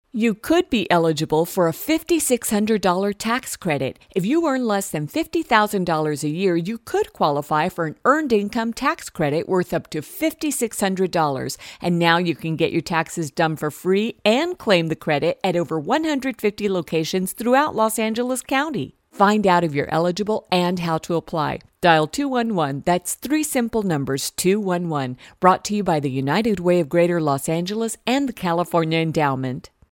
Below, 4 Radio Spots (English and Spanish versioins) for The United Way